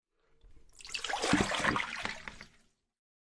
家政服务 " 水溢出
描述：水溢出